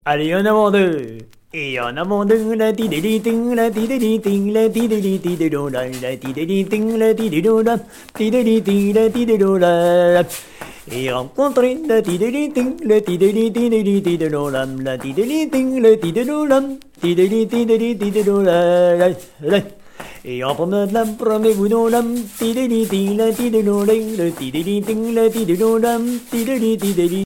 En avant-deux avec danseurs
danse : branle : avant-deux ;
Pièce musicale éditée